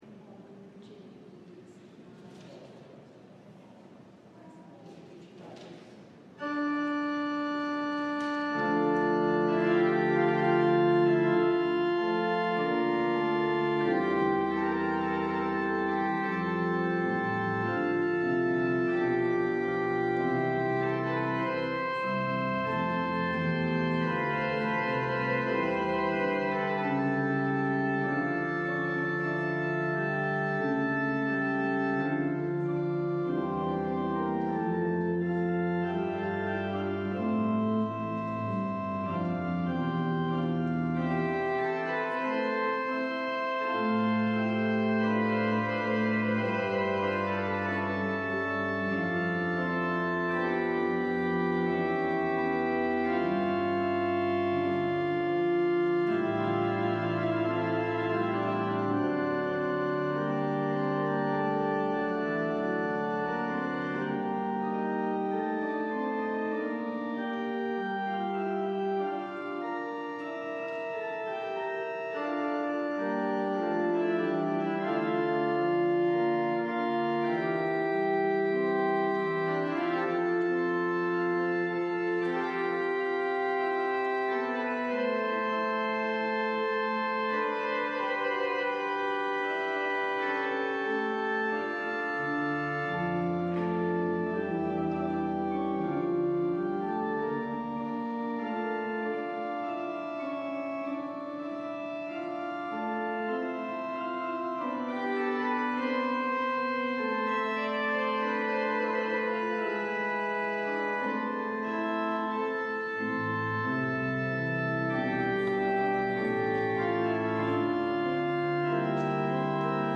LIVE Evening Worship Service - Wondering & Confirmation